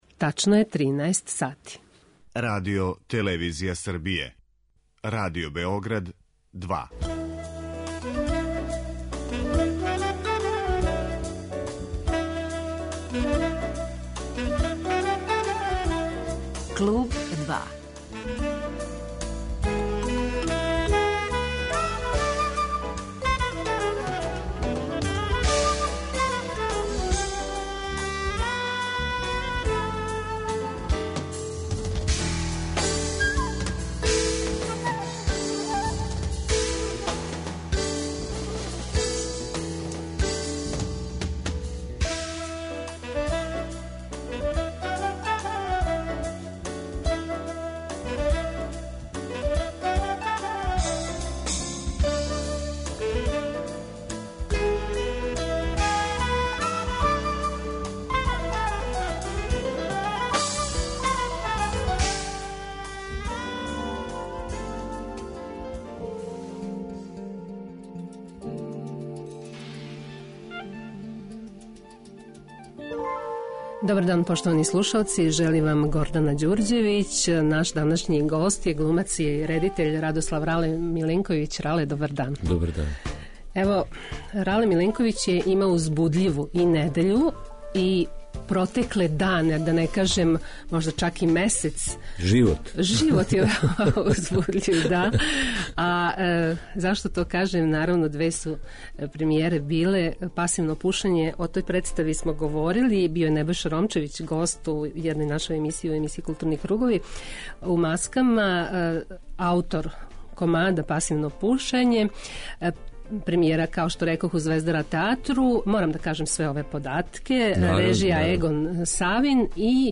Гост 'Клуба 2' је Радослав Рале Миленковић.